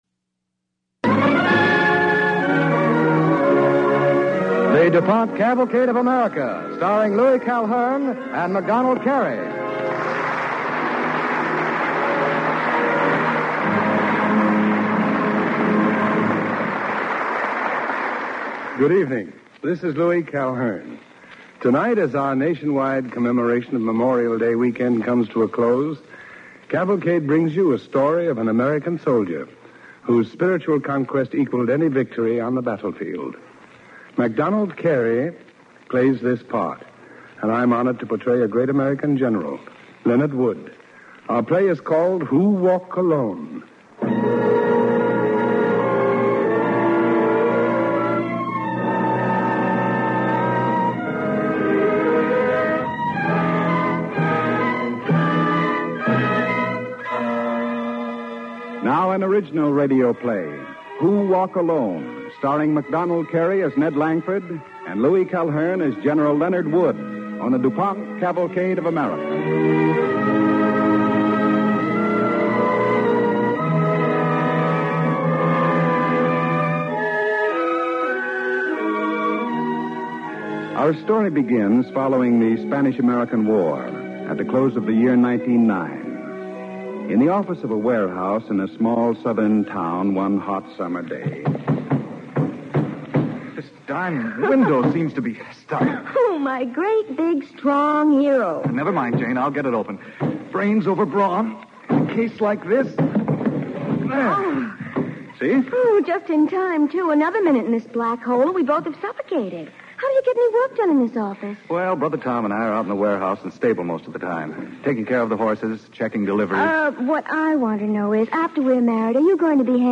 starring MacDonald Carey and Louis Calhern